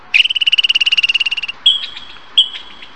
Tupai_Suara.ogg